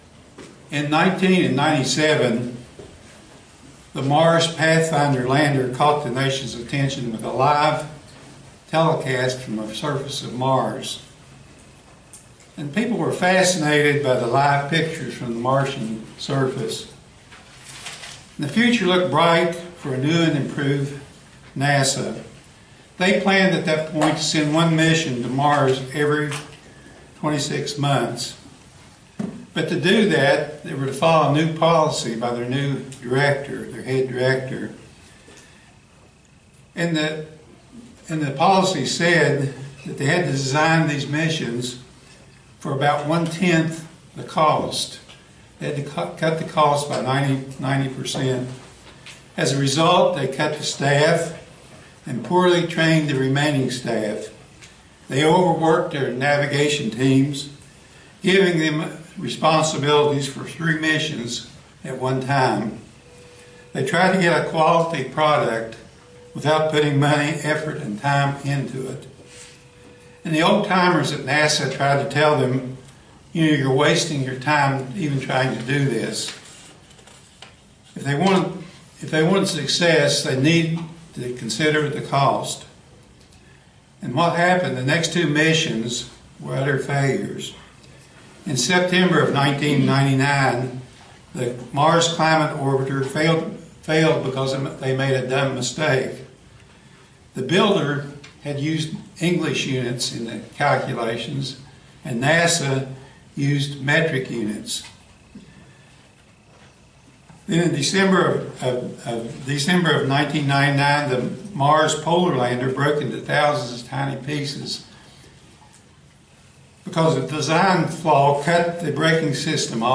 Sermons
Given in Roanoke, VA